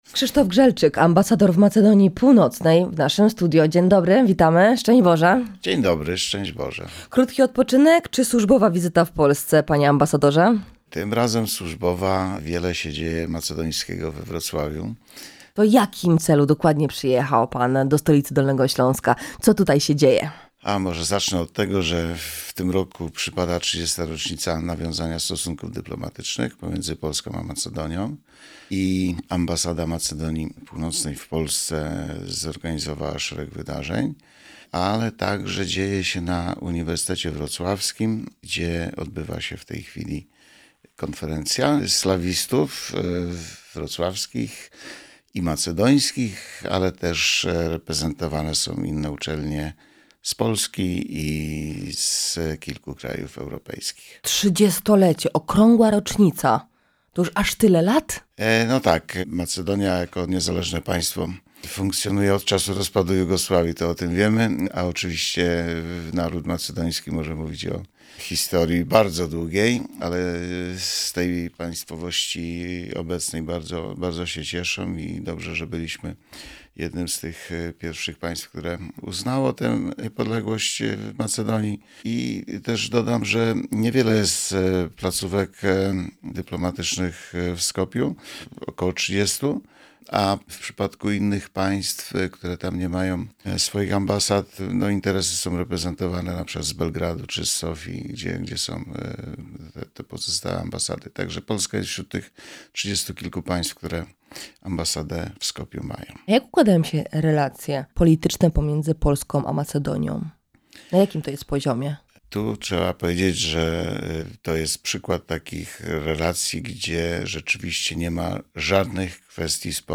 W tym roku obchodzimy 30. rocznicę nawiązania stosunków polsko-macedońskich. Krzysztof Grzelczyk - ambasador w Macedonii Północnej w Radio Rodzina mówi o wydarzeniach w stolicy Dolnego Śląska, a także o relacjach politycznych między krajami. Powiemy także o ruchu turystycznym oraz atutach bałkańskiego państwa.